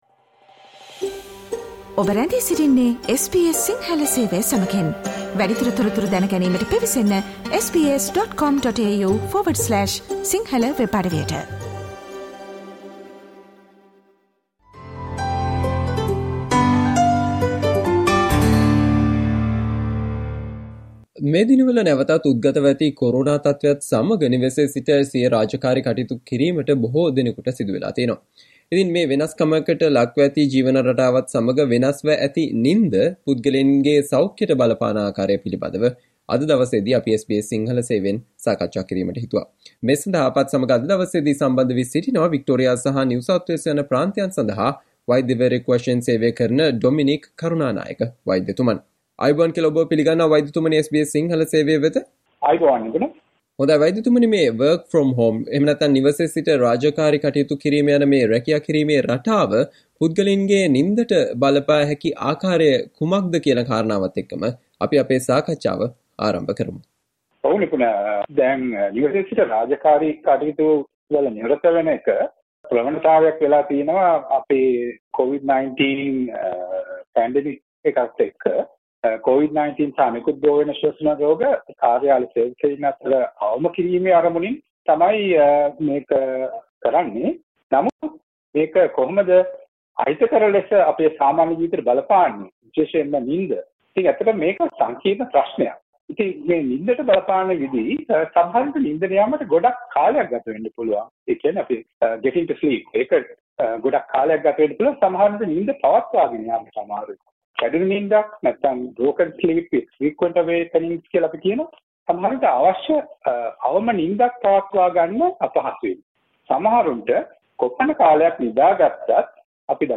Listen to the discussion conducted by SBS Sinhala on What you need to know about the value of your sleep which can change while working from home